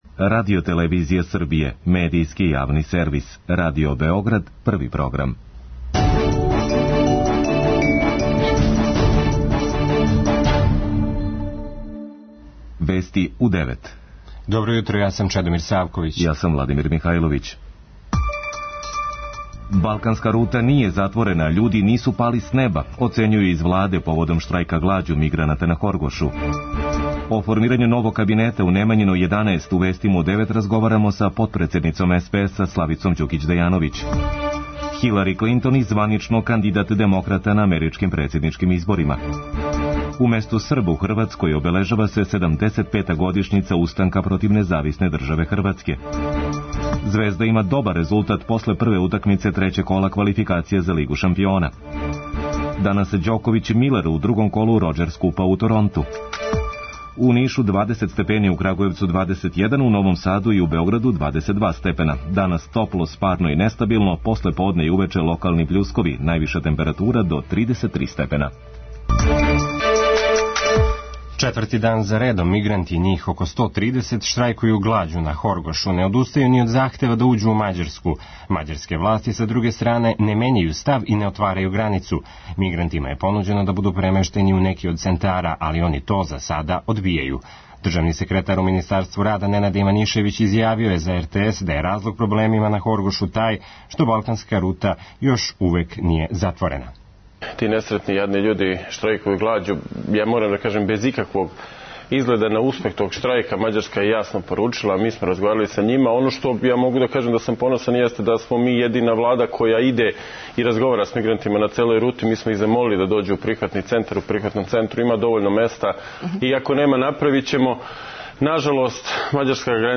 О формирању новог кабинета, у Вестима разговарамо са потредседницом СПСа- Славицом Ђукић Дејановић.